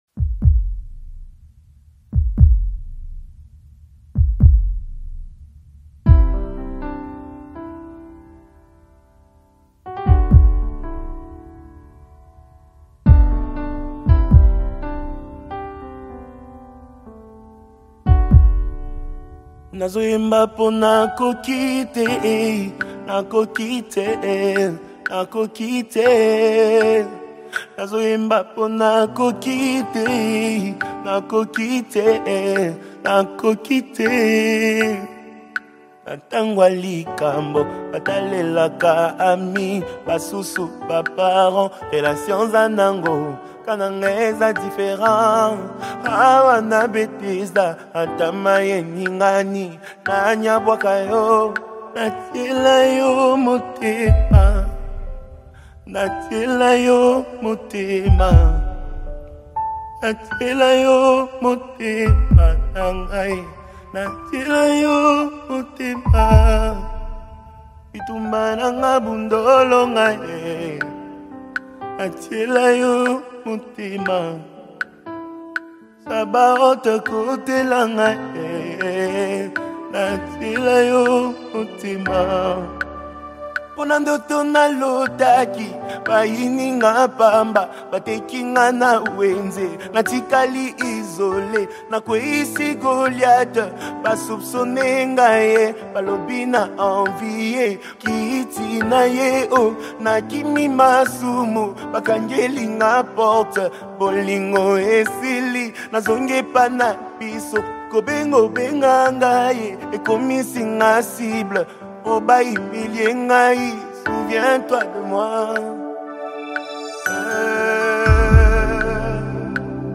SPIRIT-FILLED WORSHIP ANTHEM